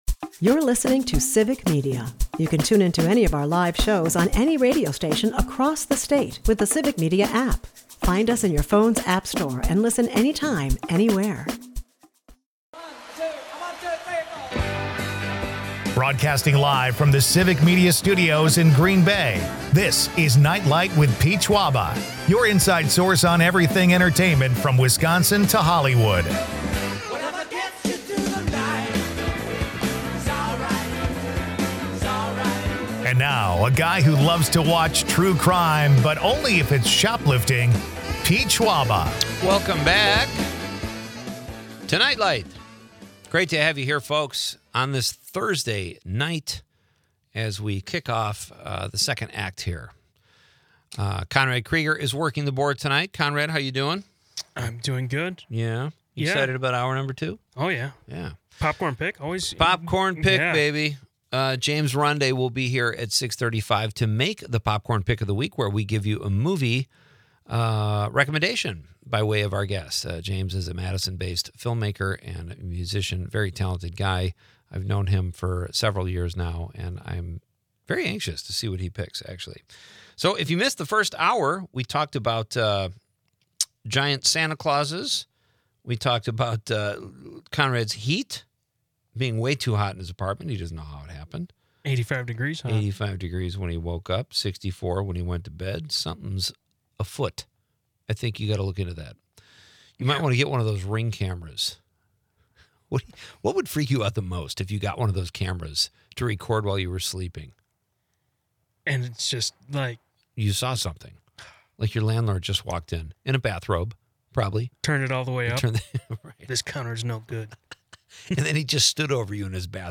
Civic Media radio network